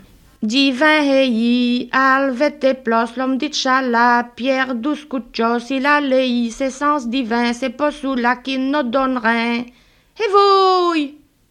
Genre : chant Type : chanson narrative ou de divertissement
Lieu d'enregistrement : Tilff (Esneux)
Support : bande magnétique